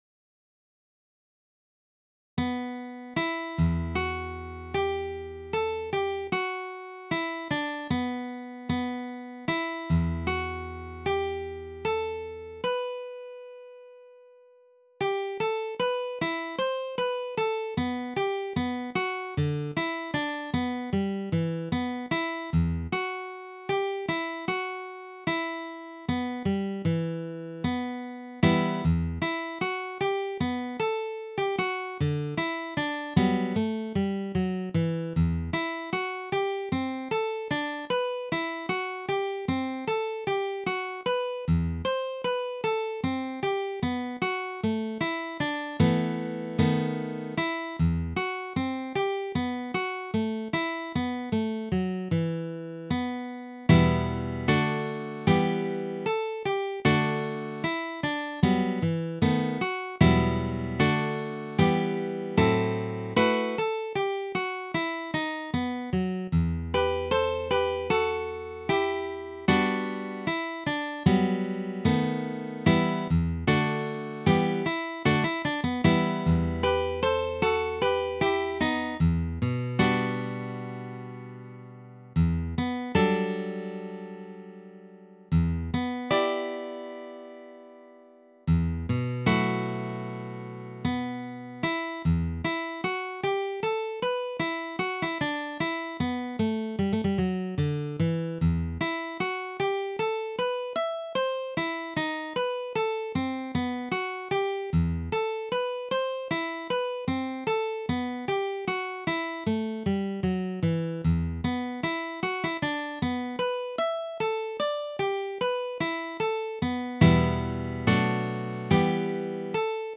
DIGITAL SHEET MUSIC - FLATPICK/PLECTRUM GUITAR SOLO
Sacred Music, Preludes, Graduals, and Offertories
Dropped D tuning